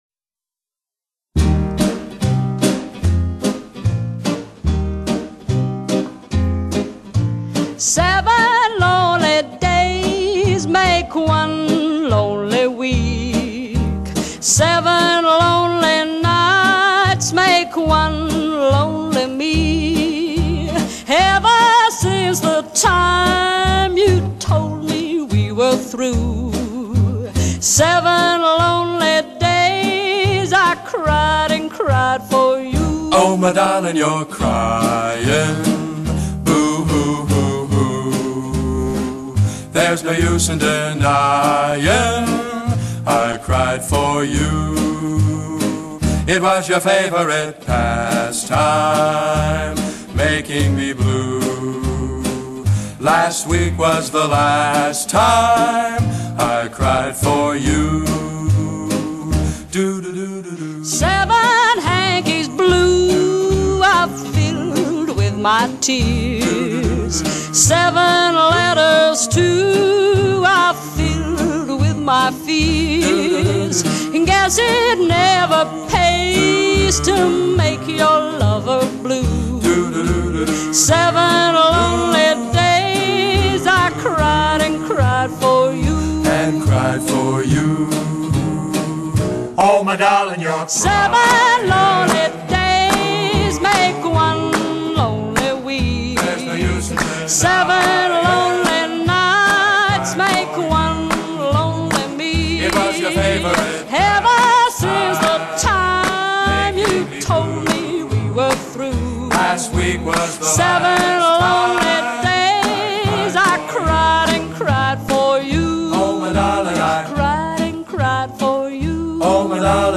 Genre:Vocal Pop,Traditional Pop